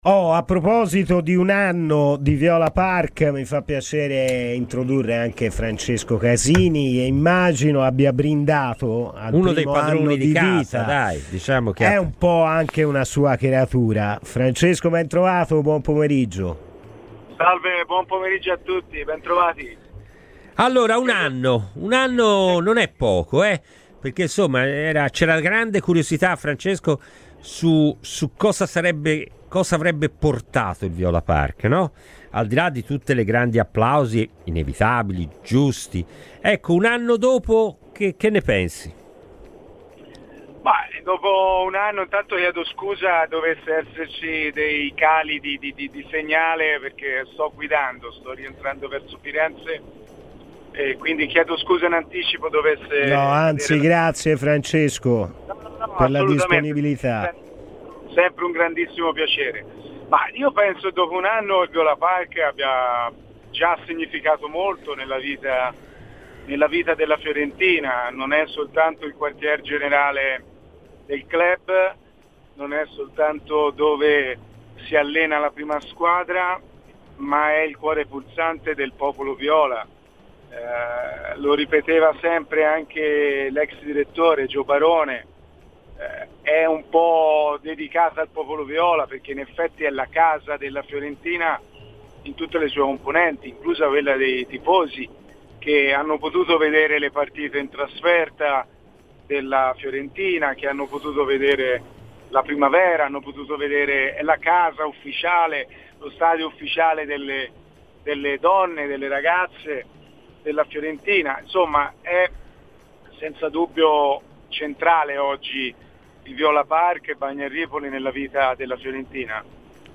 Francesco Casini, Consigliere Comunale di Palazzo Vecchio ed ex sindaco di Bagno a Ripoli, è intervenuto a Radio FirenzeViola, durante "Palla al Centro", per parlare del Viola Park e del suo primo compleanno: "Penso che dopo un anno il Viola Park abbia già inciso molto nella vita della Fiorentina.